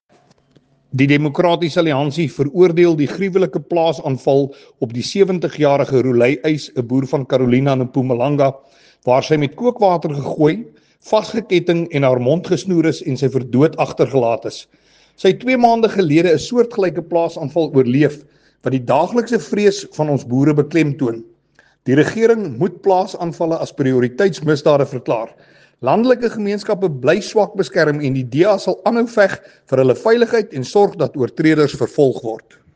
Afrikaans soundbites by Willie Aucamp MP.